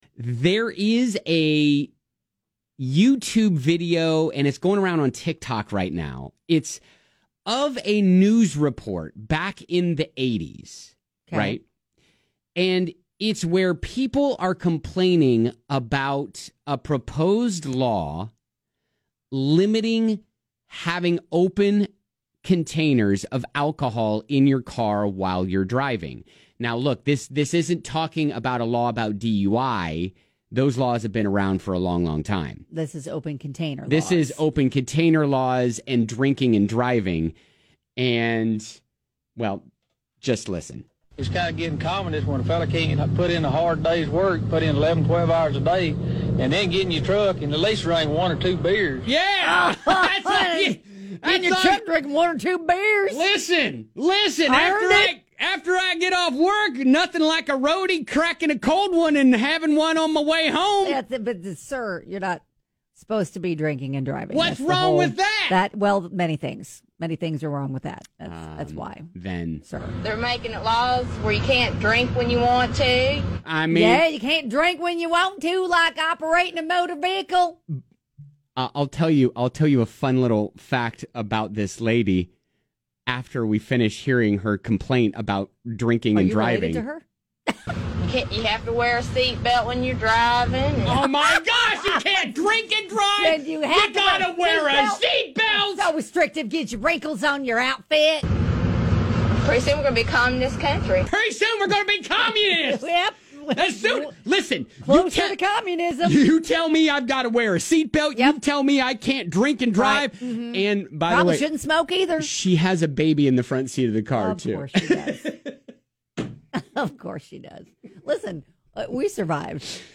Here's a throwback news report in the 1980s where people complained about the proposed new laws limiting drinking alcohol while driving.